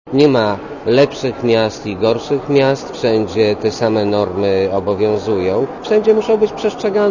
Nie będziemy pobłażać takim sytuacjom - zapewnia rzecznik klubu SLD Jerzy Wenderlich.